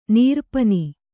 ♪ nīrparni